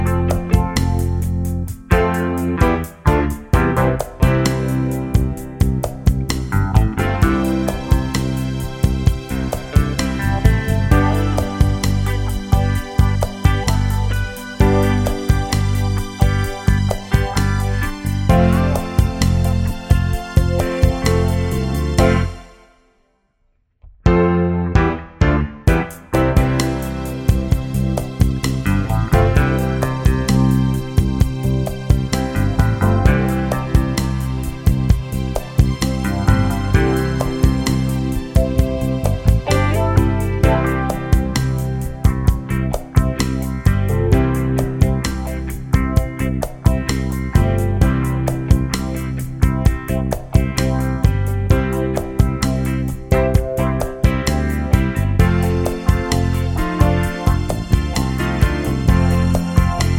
no Backing Vocals Disco 4:07 Buy £1.50